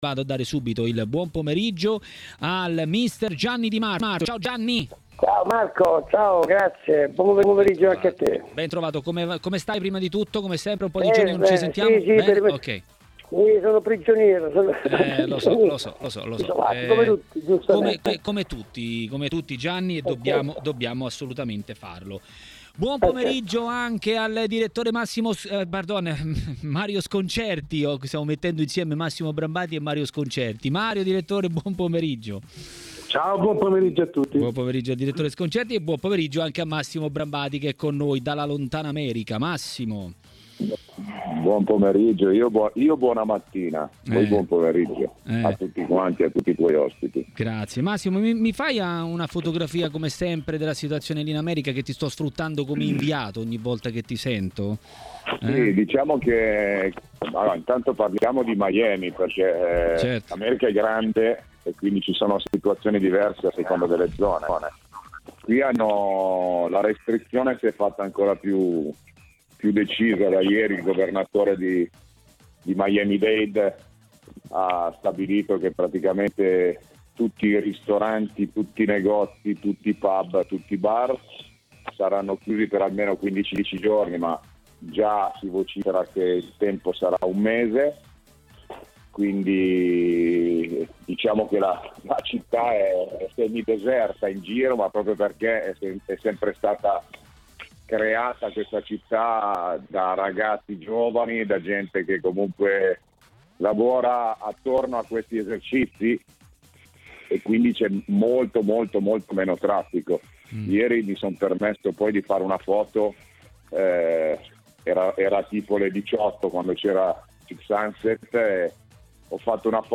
Per parlare della situazione attuale è intervenuto a TMW Radio, durante Maracanà, il direttore Mario Sconcerti.